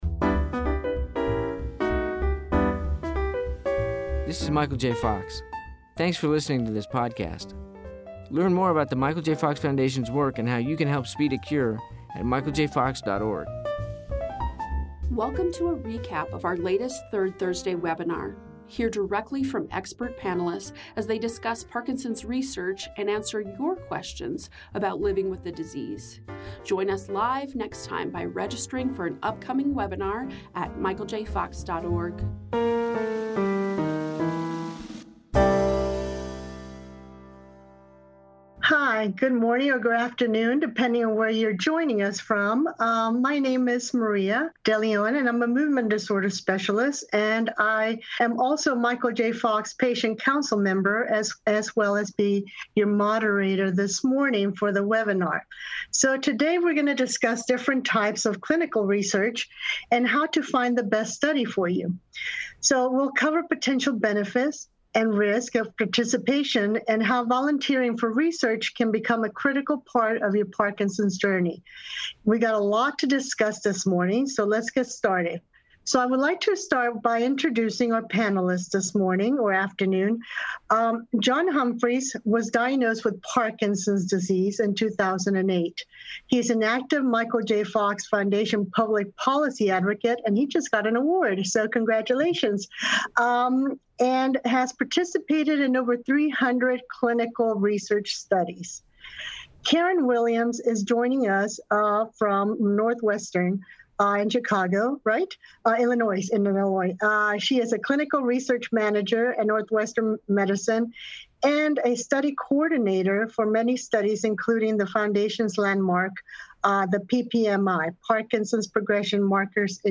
Hear our expert panelists discuss the benefits and risks of participating in Parkinson’s research.